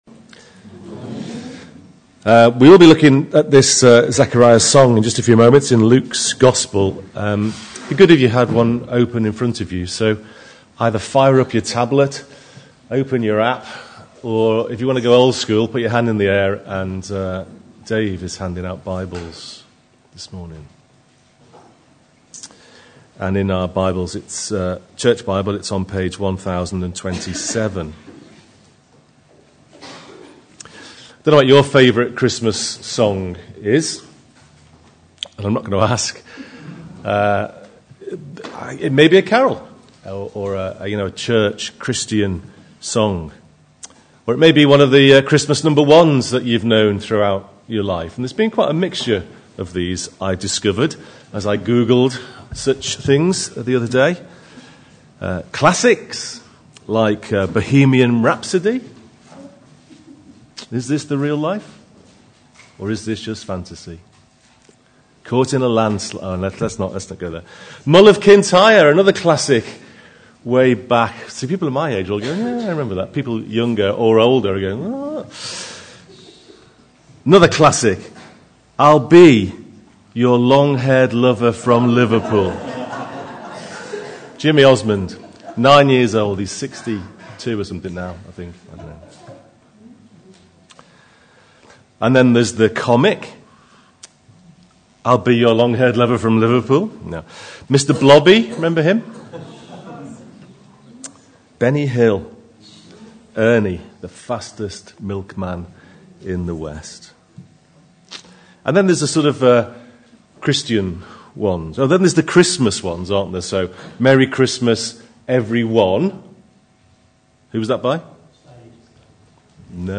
Saltisford Church, Warwick.